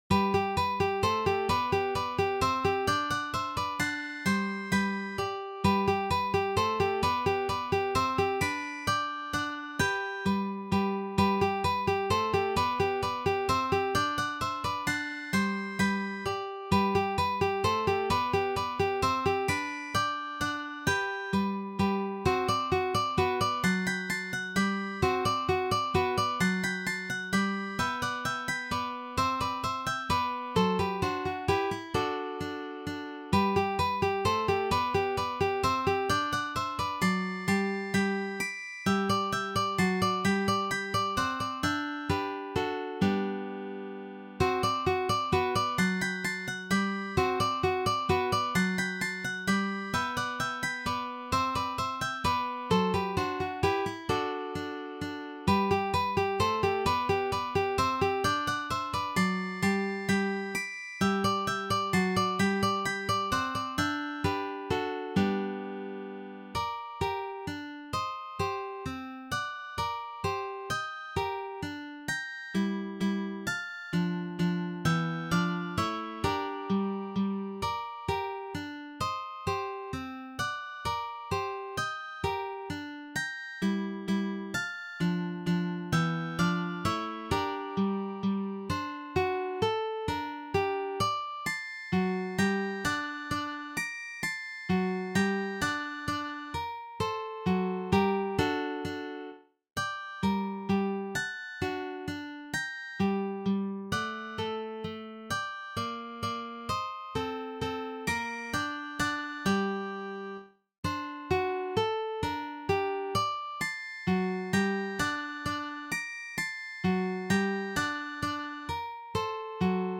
in G Major